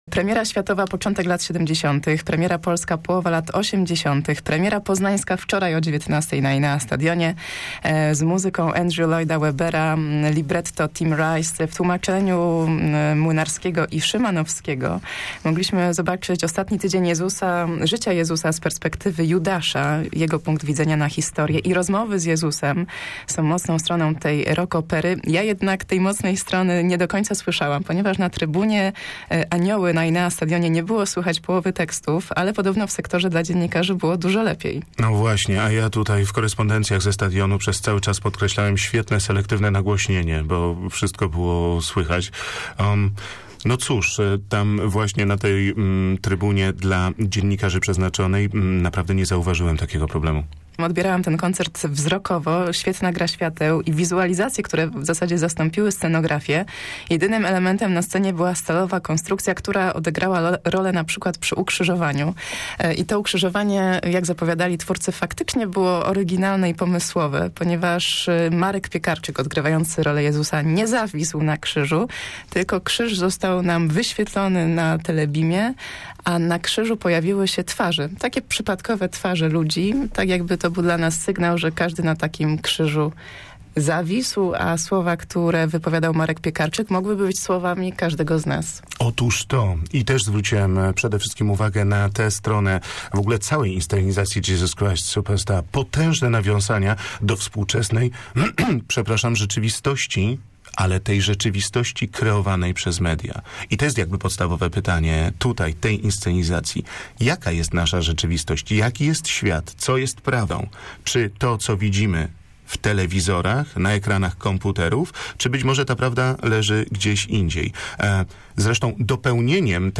Recenzja